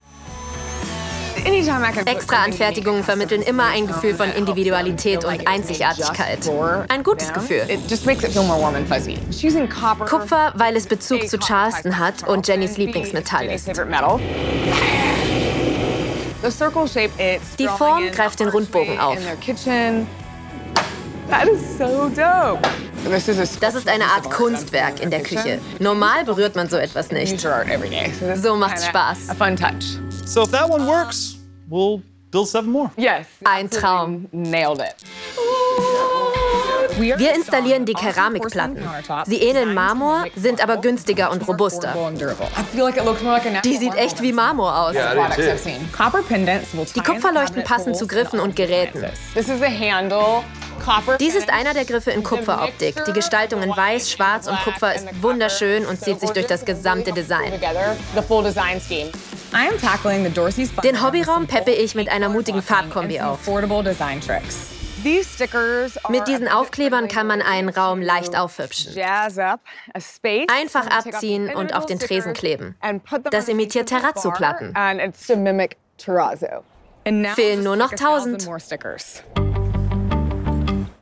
sehr variabel
Jung (18-30)
Sächsisch
Voice Over optimistisch, schwungvoll
Doku